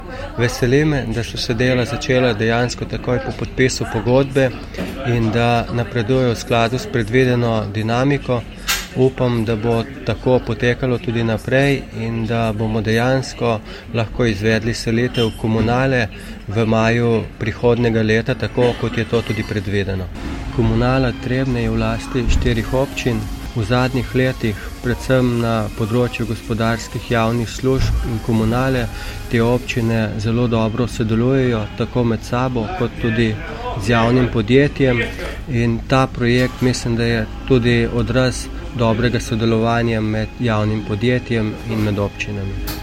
SPOROČILO ZA JAVNOST
izjava_za_javnost.mp3 (699kB)